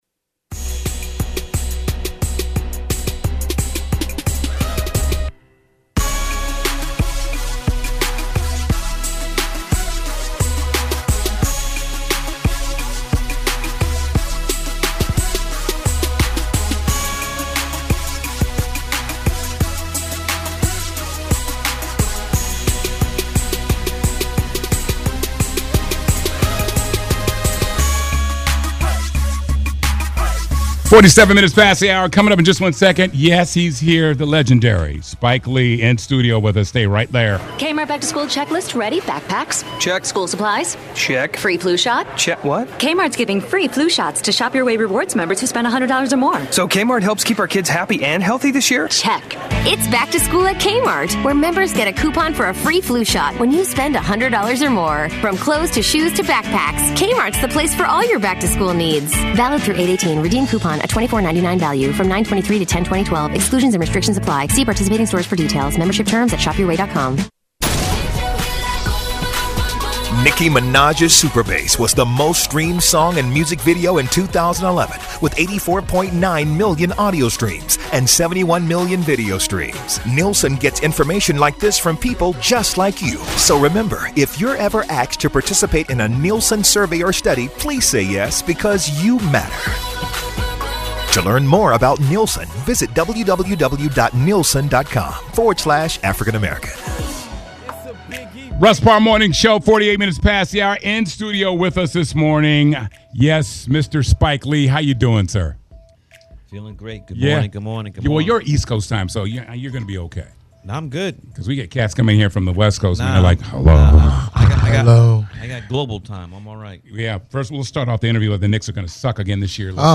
Spike Lee Sits Down With Russ Parr
spike-leee-interview.mp3